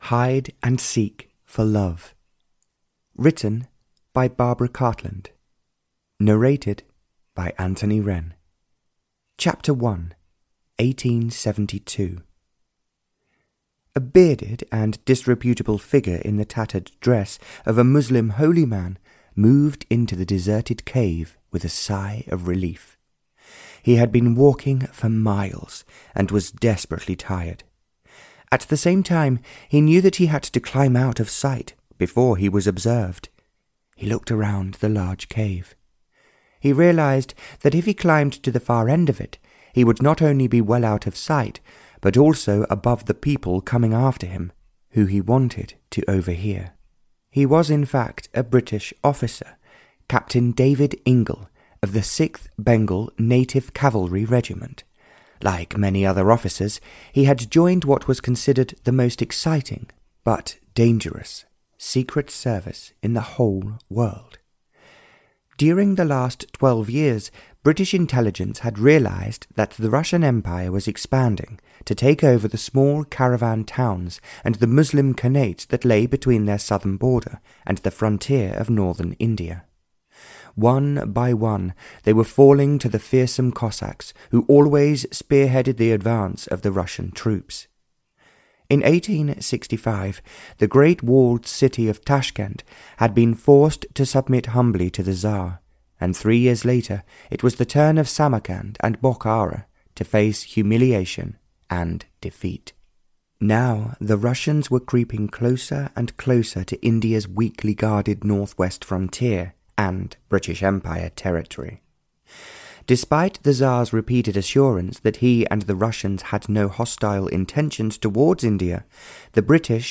Hide and Seek for Love (Barbara Cartland’s Pink Collection 69) (EN) audiokniha
Ukázka z knihy